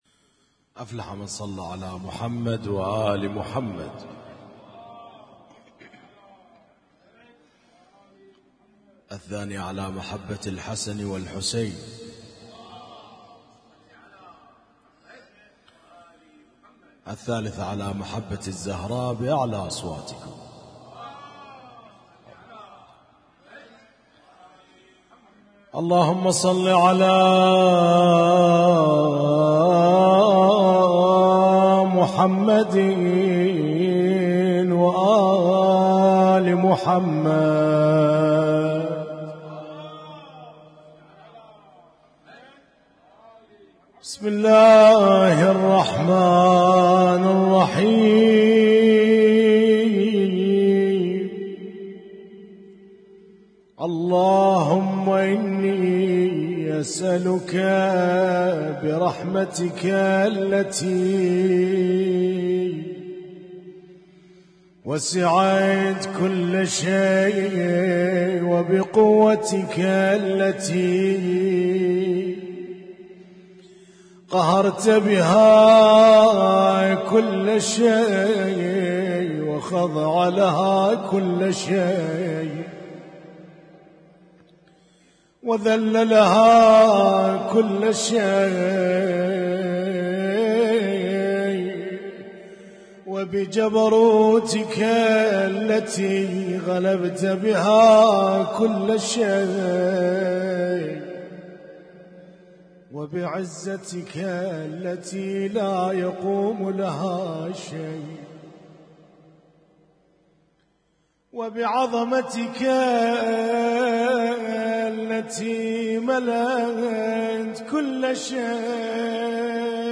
Husainyt Alnoor Rumaithiya Kuwait
اسم التصنيف: المـكتبة الصــوتيه >> الادعية >> دعاء كميل